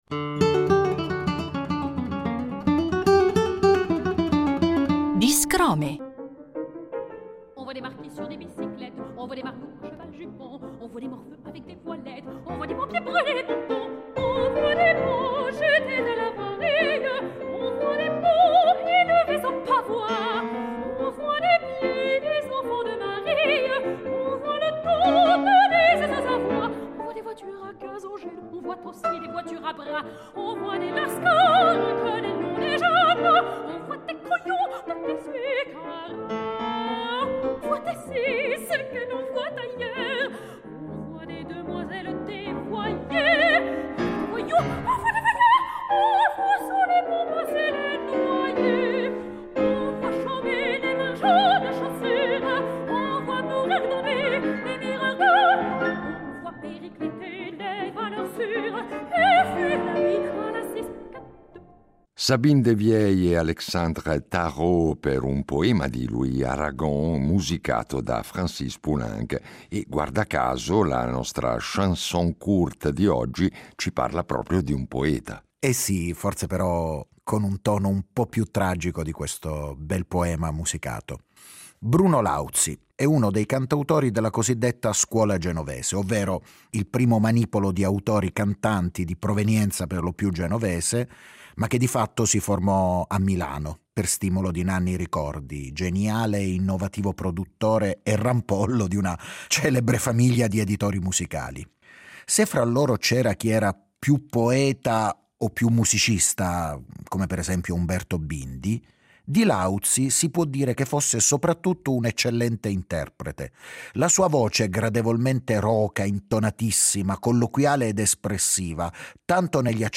Vi facciamo ascoltare queste canzoni, in versione discografica o live, raccontandovi qualcosa dei loro autori e del contesto in cui nacquero… poesia, musica e storie in cinque minuti: un buon affare!